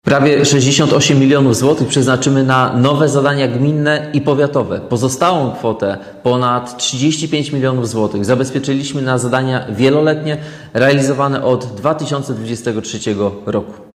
– mówi wicewojewoda Bartosz Brożyński.